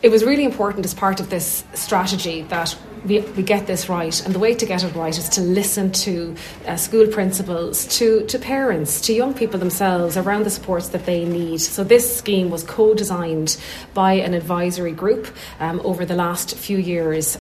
Education Minister Hildegarde Naughton says the measures are designed to strengthen supports where they’re needed most: